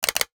NOTIFICATION_Click_03_mono.wav